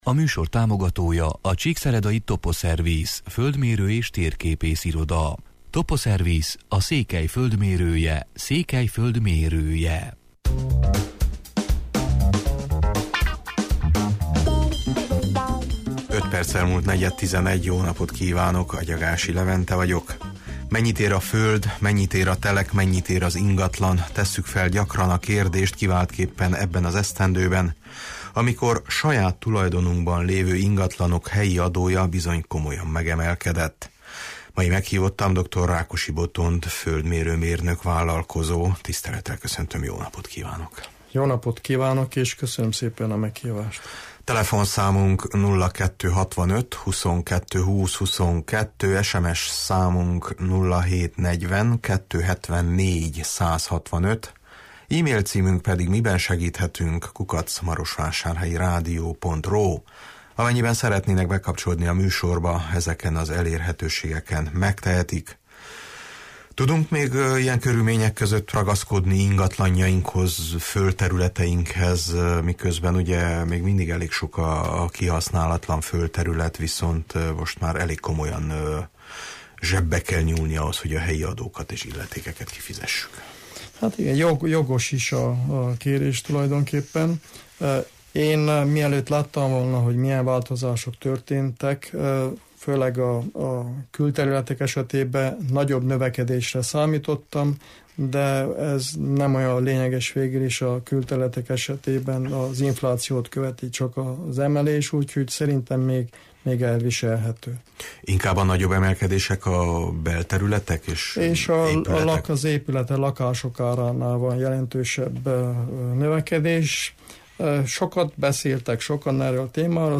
Gyakran tesszük fel a kérdést, kiváltképpen ebben az esztendőben, amikor saját tulajdonunkban lévő ingatlanok helyi adója bizony komolyan megemelkedett. Befolyásolja-e az adóemelés az ingatlanok árát, befolyásolják-e a változások az ingatlanpiacot? – erről beszélgetünk.